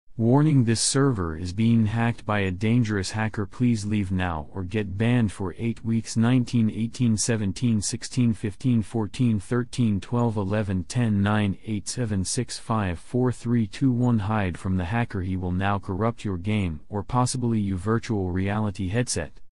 Gorilla Tag Warning Sound Effect Free Download